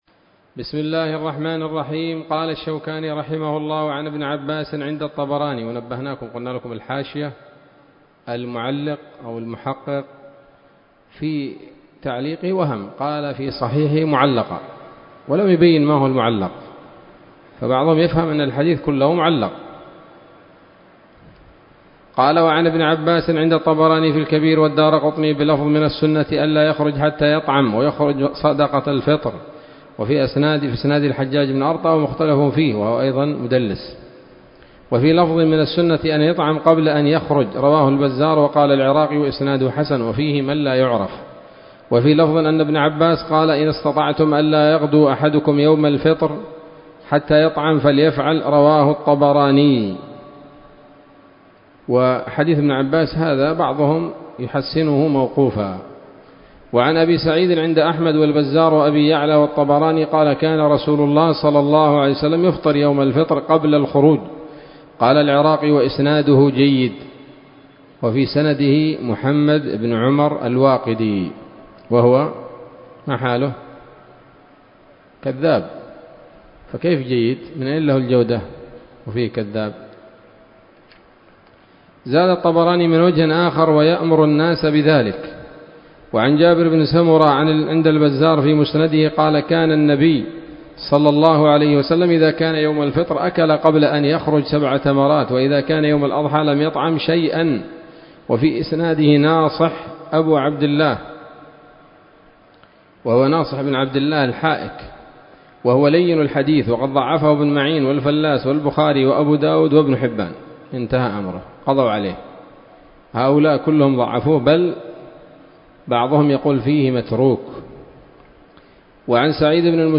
الدرس الرابع من ‌‌‌‌كتاب العيدين من نيل الأوطار